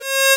8Bit声音 " Beep2
描述：一声嘟嘟声 请给我一个评论，我接下来要发出声音，谢谢:)。
Tag: 8位 复古 芯片 音色 sarcade